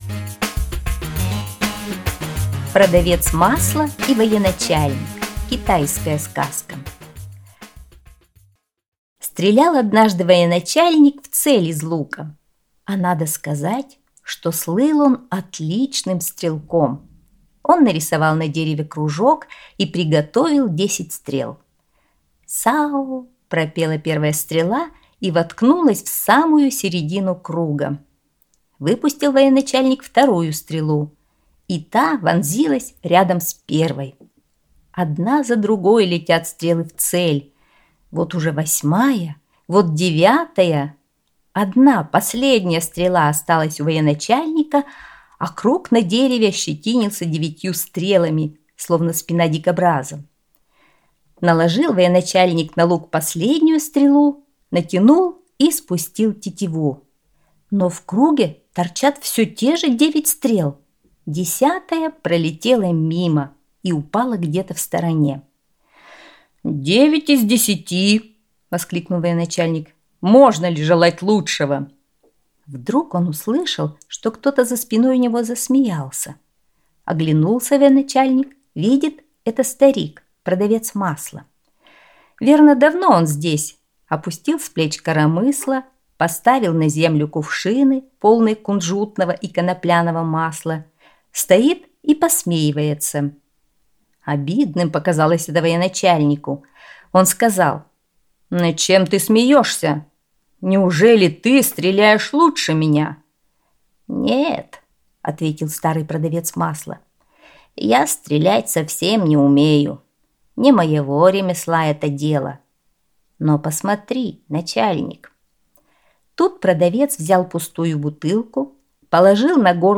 Продавец масла и военачальник – китайская аудиосказка